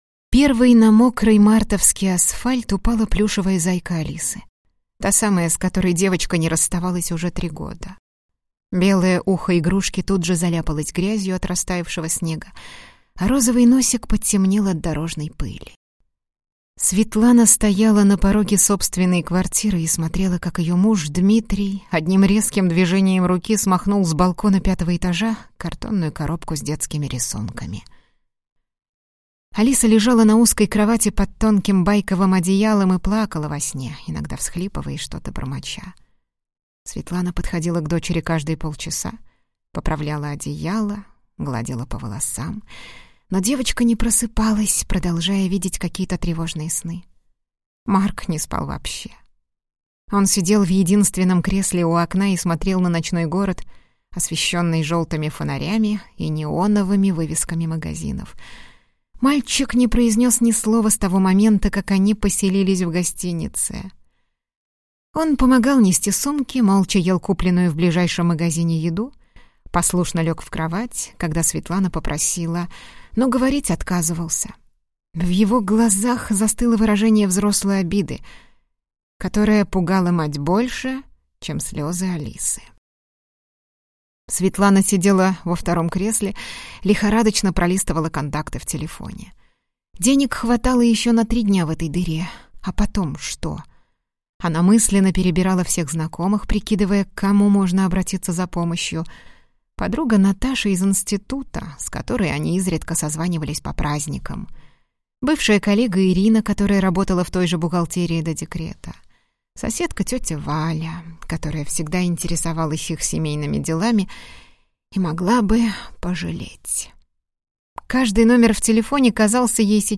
Запись с дикторами Rideró